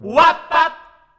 WAB BAB.wav